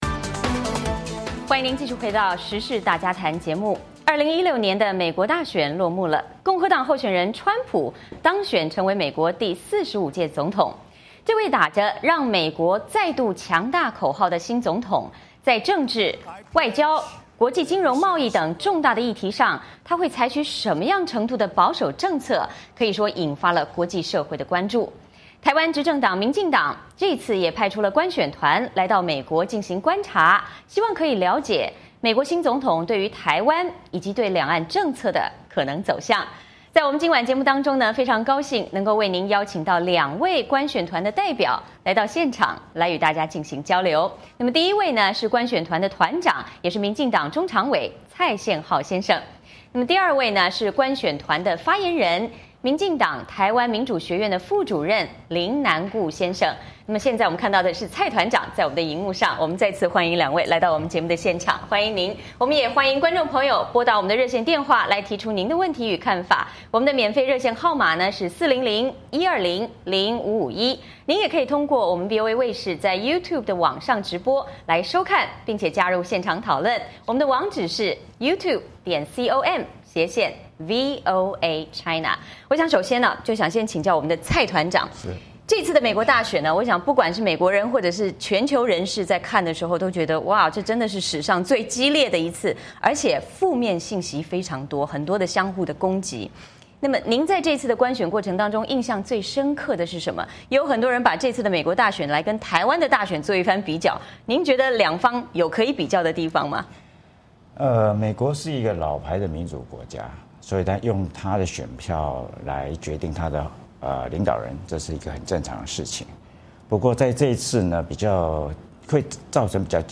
台湾执政党民进党也派出观选团到美国进行观察，希望了解美国新总统对台湾以及两岸政策的可能走向。今晚节目中很高兴为您邀请到两位观选团代表来到现场与大家进行交流。